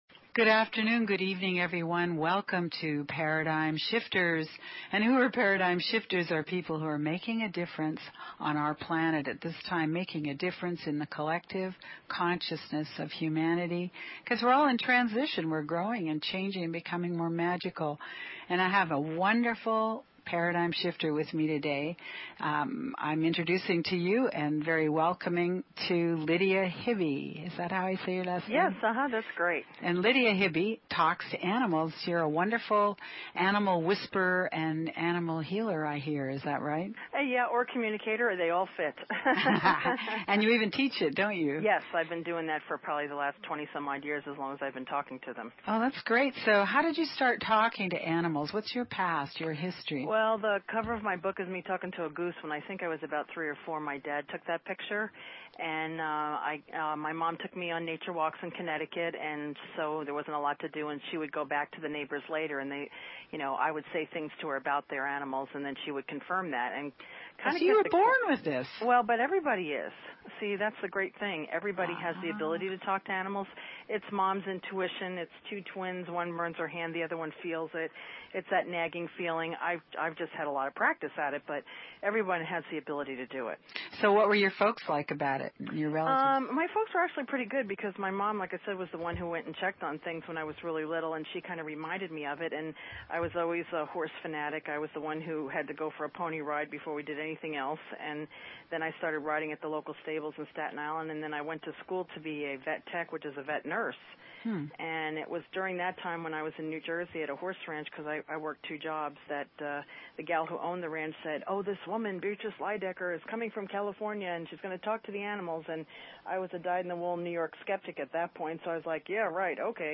Talk Show Episode, Audio Podcast, Paradigm_Shifters and Courtesy of BBS Radio on , show guests , about , categorized as